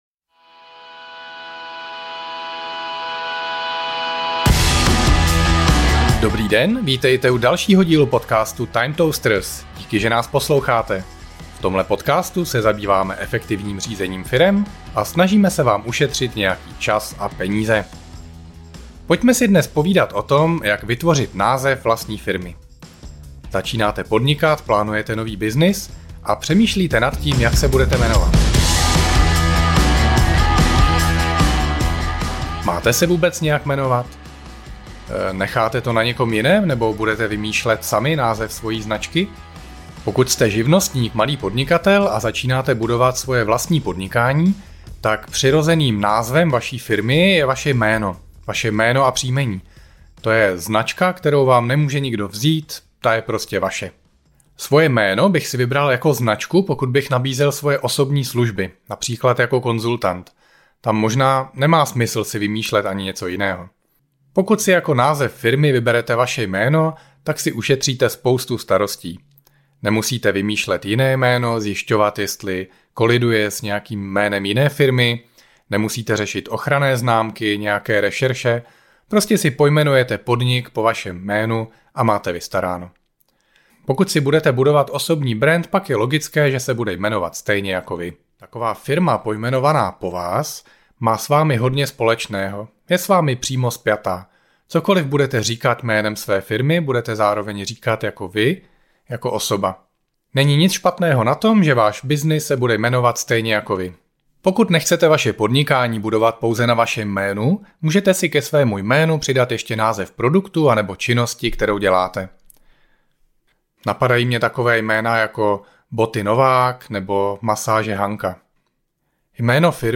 V této sólo epizodě vám dám tipy na to, jak si vytvořit název vlastní firmy.